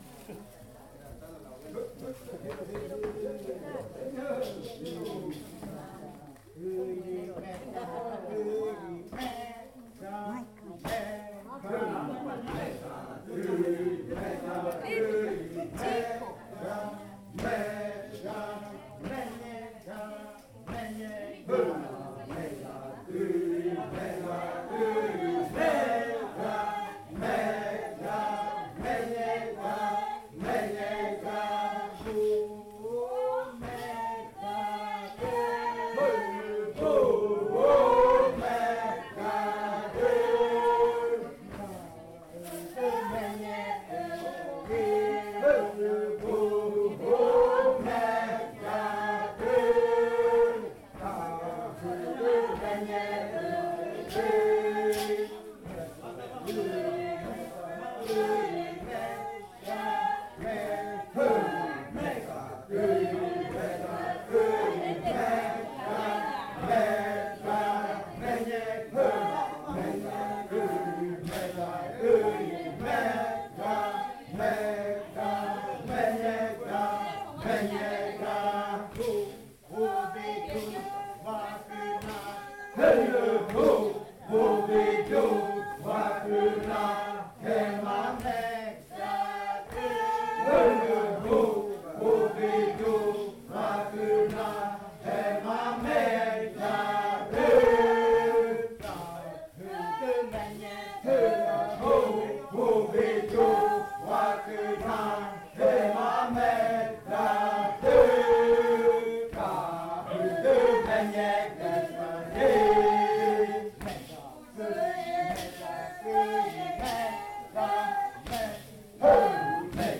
Canto de la variante muruikɨ
Leticia, Amazonas
con el grupo de cantores bailando en Nokaido.
with the group of singers dancing in Nokaido.